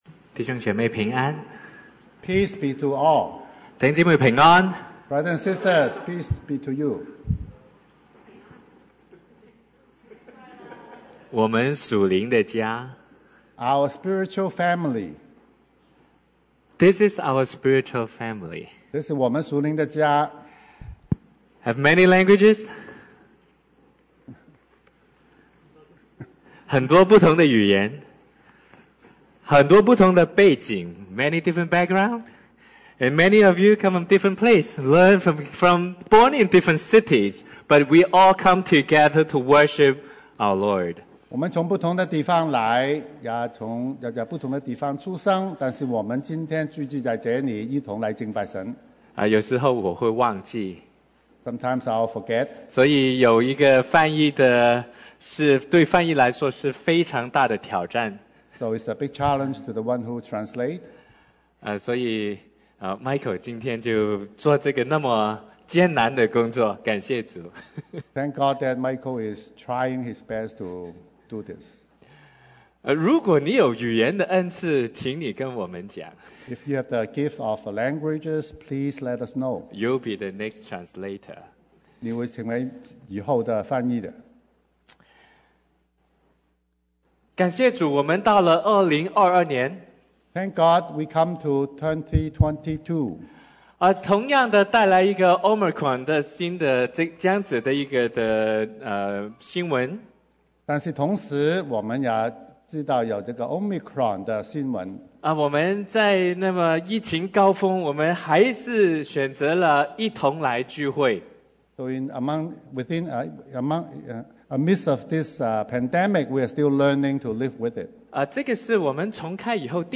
1/2/2022 國粵英語聯合崇拜: 我們屬靈的家